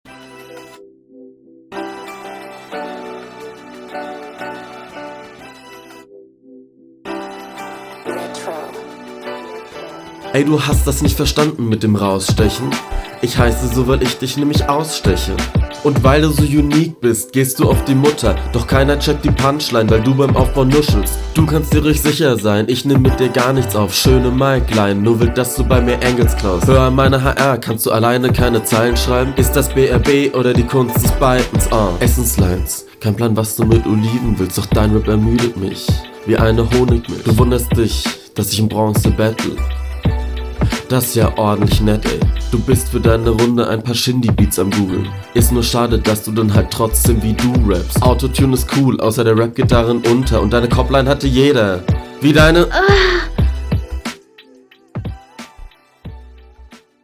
Audio klingt ganz schlimm. Wie nah am Mic hast du aufgenommen?